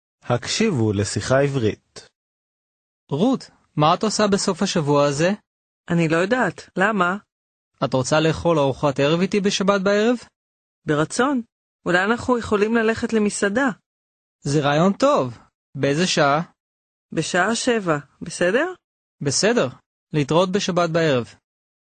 Аудио курс для самостоятельного изучения иврита.